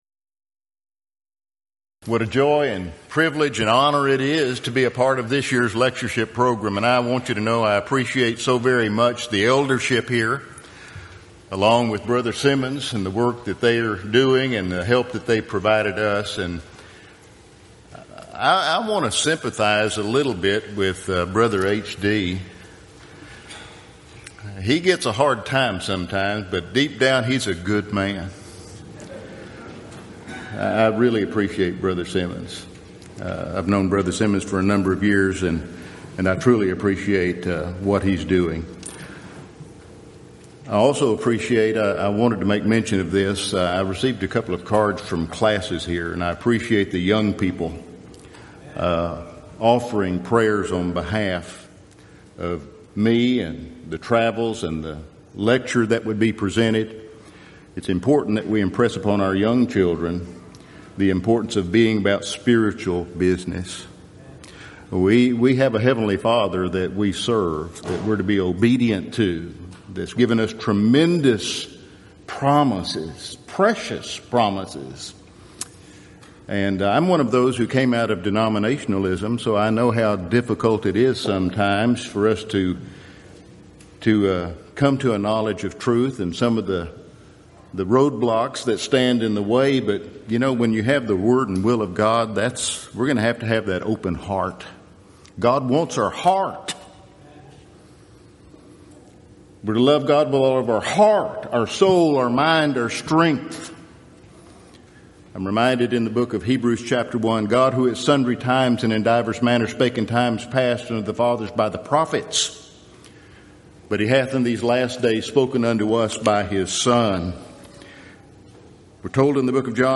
Event: 23rd Annual Gulf Coast Lectures
lecture